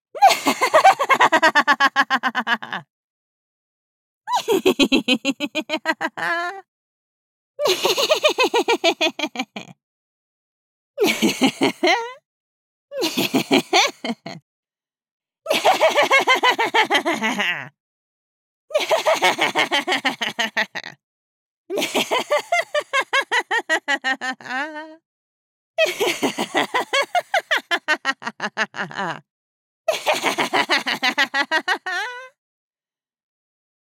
Maniacal Laughter Pack 1
maniacal_laughter_pack_1.ogg